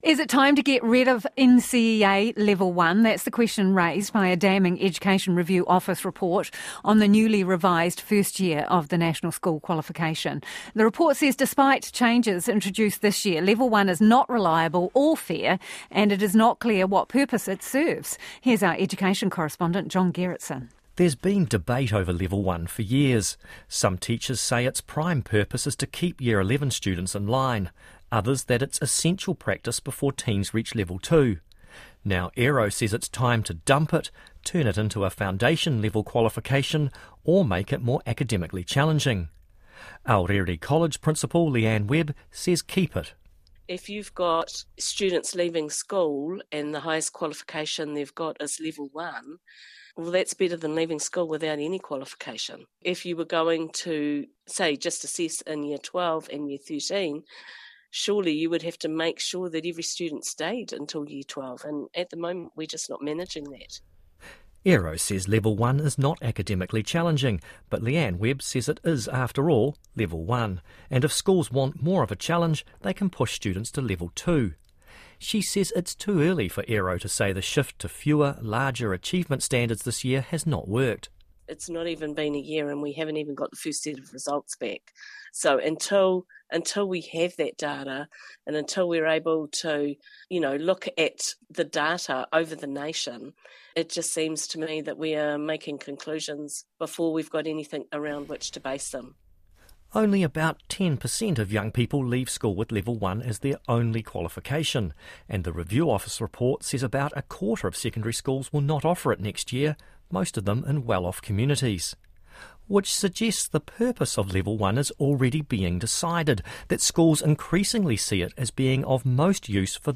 RNZ features more of their interview